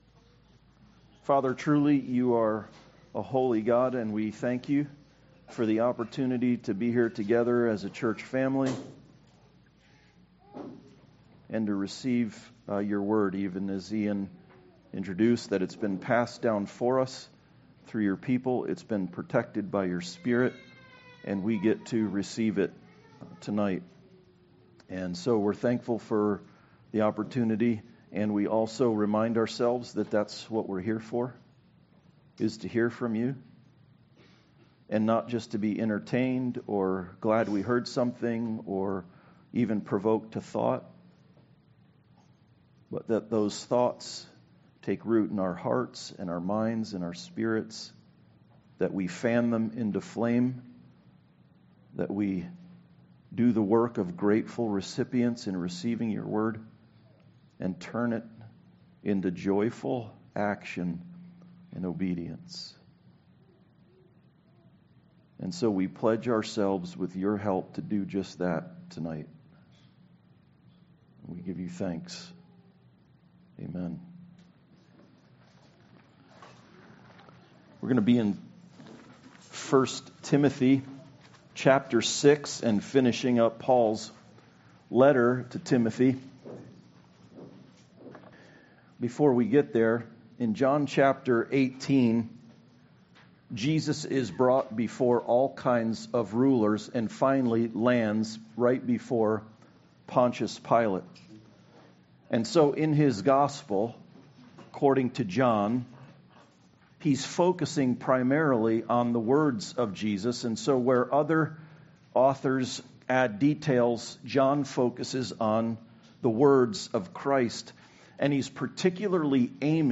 1 Timothy 6:11-21 Service Type: Sunday Service The good confessions is